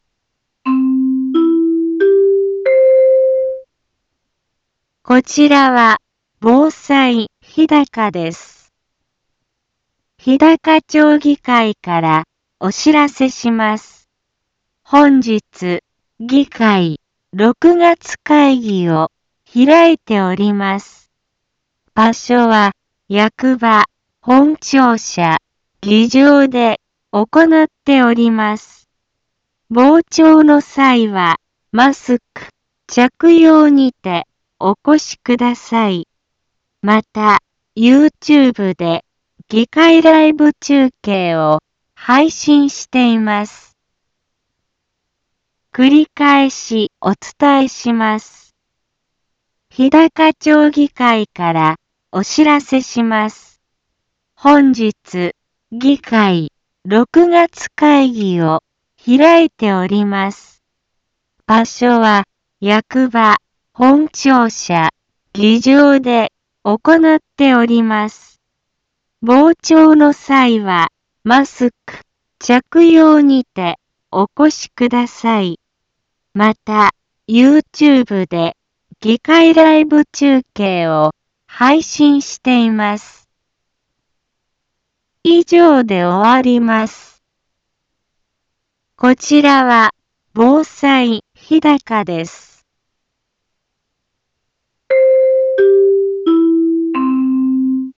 一般放送情報
Back Home 一般放送情報 音声放送 再生 一般放送情報 登録日時：2022-06-08 10:03:28 タイトル：日高町議会６月会議のお知らせ インフォメーション：こちらは防災日高です。